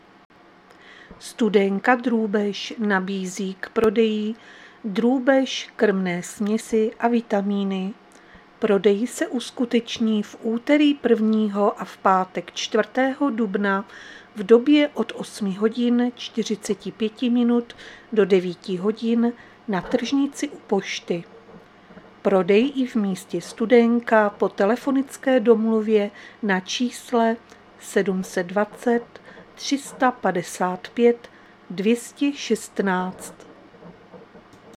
Záznam hlášení místního rozhlasu 31.3.2025
Zařazení: Rozhlas